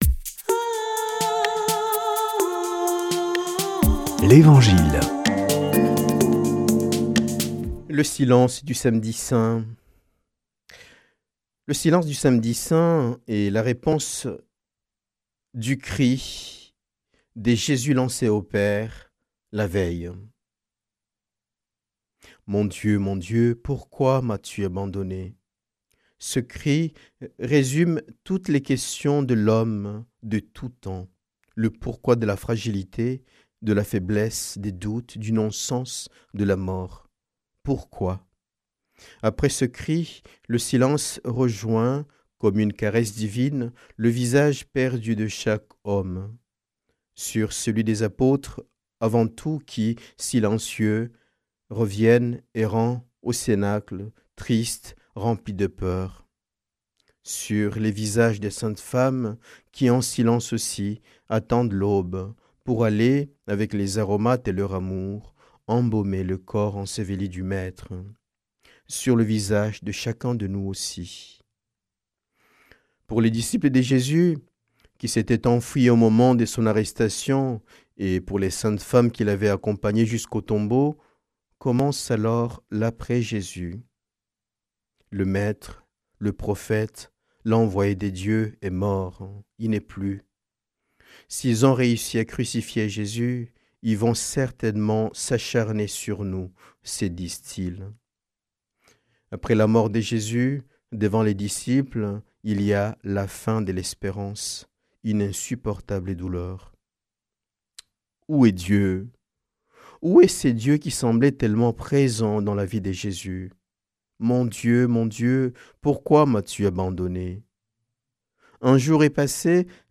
samedi 4 avril 2026 Evangile et commentaire Durée 5 min
Des prêtres de la région